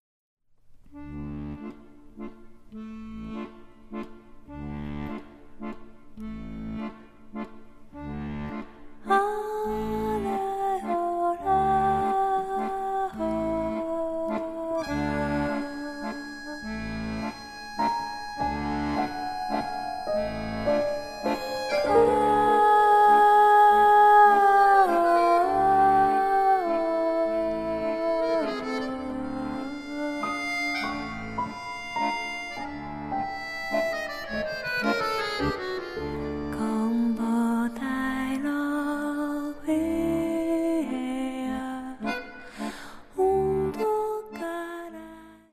voice
piano
accordion